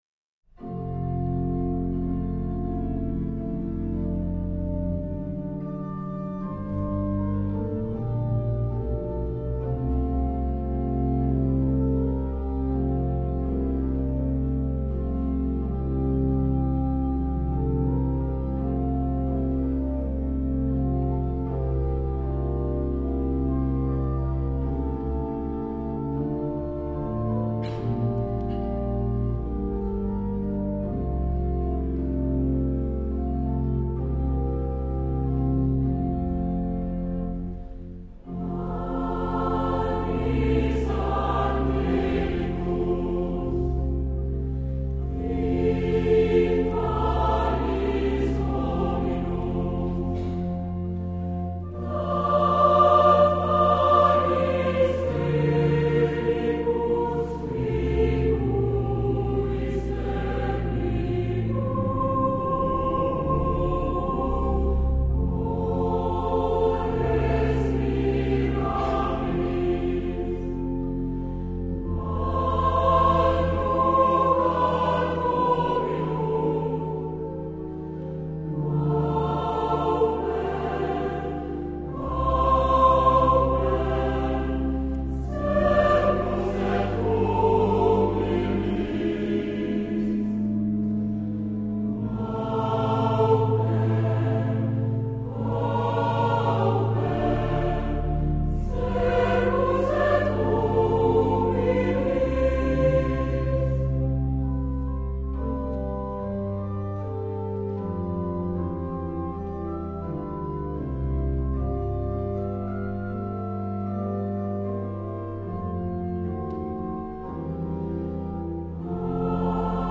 His most famous work is arguably the motet "Panis Angelicus", frequently heard at St.Edmund's (audio clip